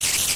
spider4.ogg